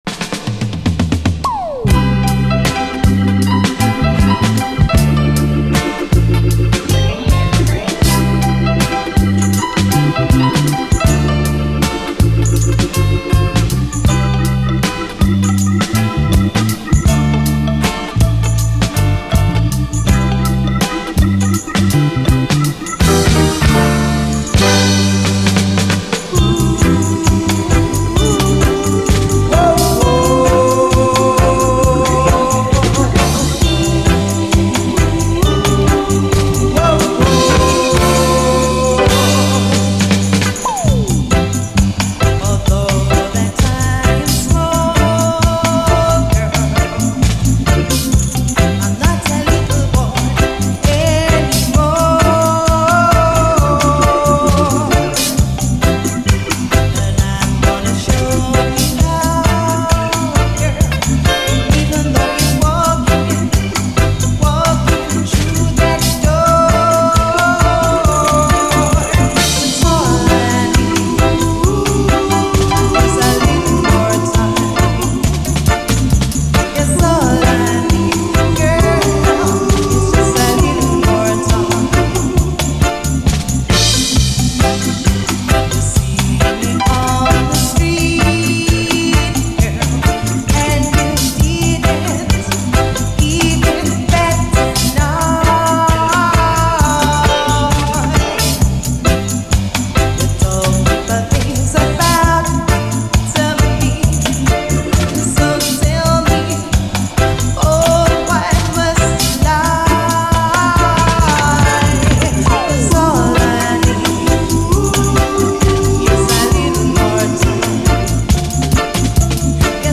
REGGAE
物憂げで切ないメロディーなのに、楽しく弾むステッパー・ラヴァーズというアンビバレントな魅力が炸裂。
後半はインスト。